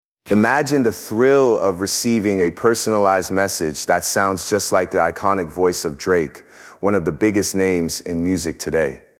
Custom Voice Message with Drake’s Voice
Provide us with the content, and our AI will generate a message that sounds like Drake himself is speaking directly to you.
• Authenticity: Our AI has been trained extensively to mimic Drake’s voice with high accuracy.
2. AI Processing: Our advanced AI algorithms process the text, transforming it into a voice message with Drake’s distinctive sound.
drake-voice.mp3